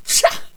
princess_attack3.wav